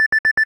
warning.ogg